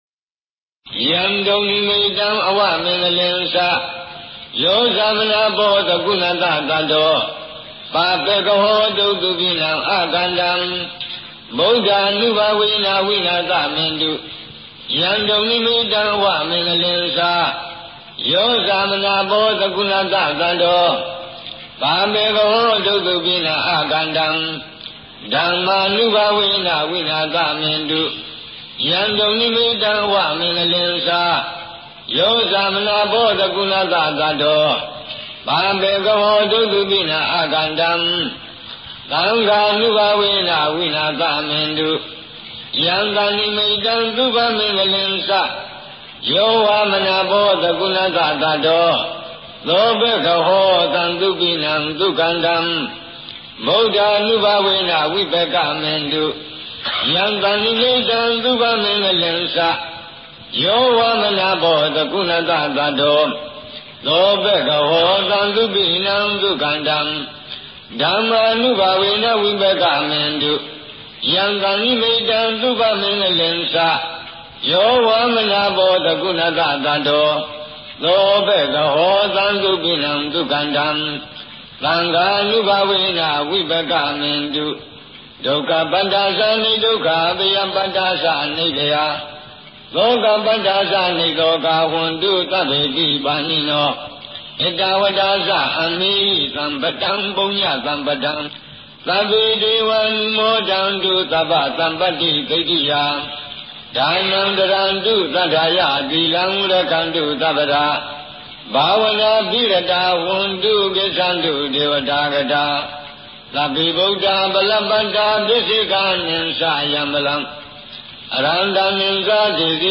PALI CHANTINGS
Paritta Sutta : protection chantings